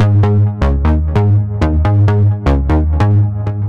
Extra Terrestrial Ab 130.wav